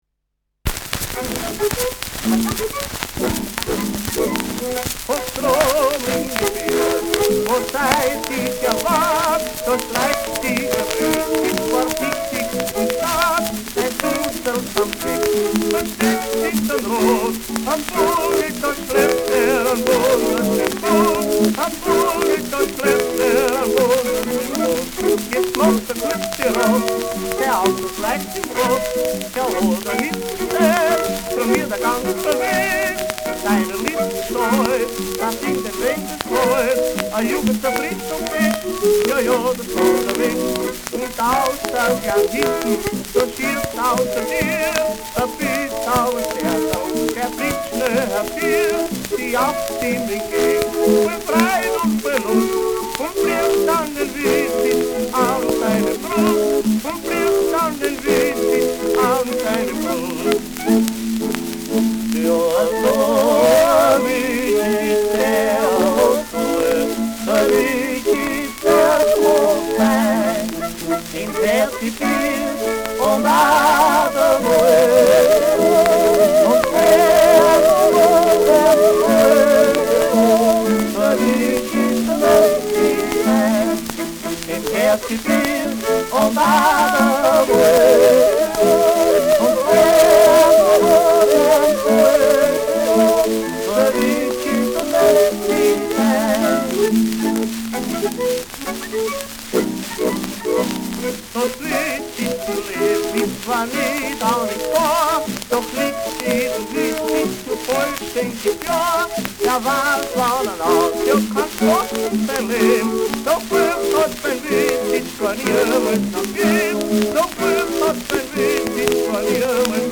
Schellackplatte
präsentes Knistern : präsentes Rauschen : Knacken zu Beginn : leichtes Schnarren : leichtes Leiern : gelegentliches Nadelgeräusch
Gebrüder Matauschek (Interpretation)
[Wien] (Aufnahmeort)